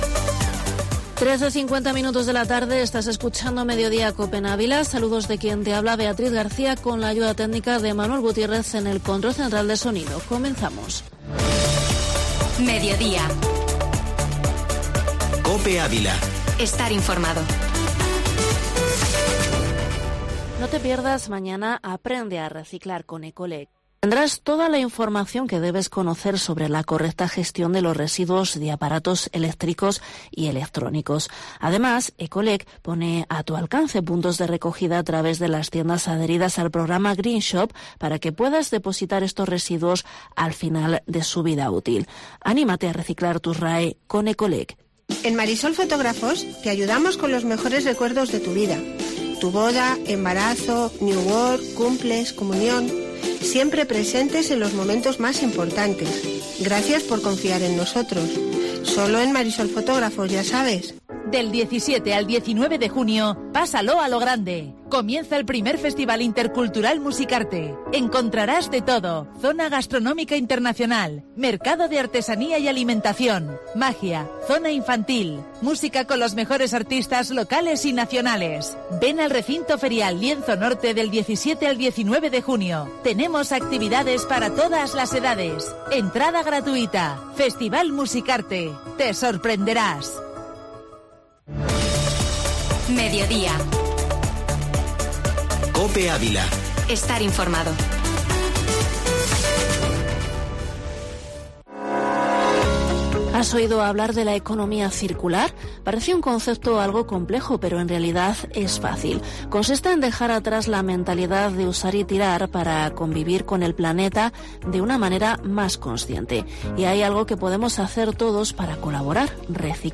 Entrevista Delegado de la JCYL